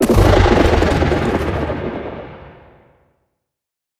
Minecraft Version Minecraft Version 1.21.4 Latest Release | Latest Snapshot 1.21.4 / assets / minecraft / sounds / mob / warden / sonic_boom2.ogg Compare With Compare With Latest Release | Latest Snapshot
sonic_boom2.ogg